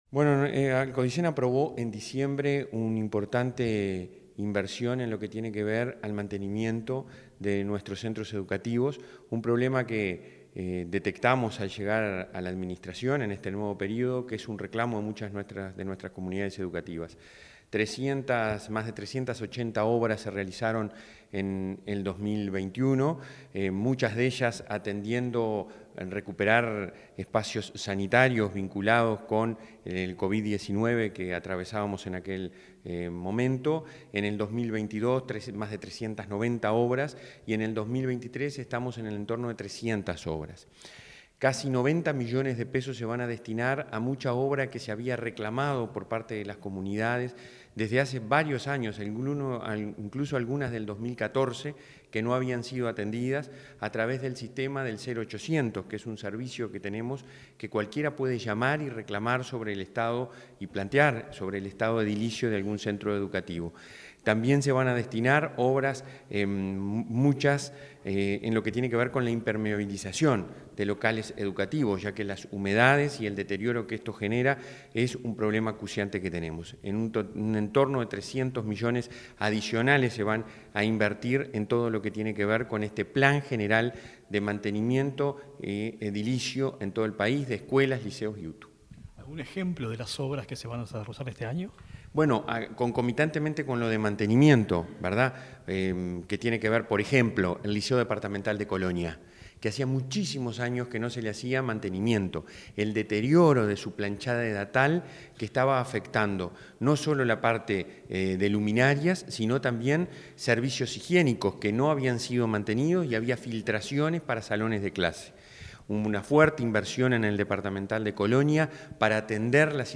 Entrevista al presidente de la ANEP, Robert Silva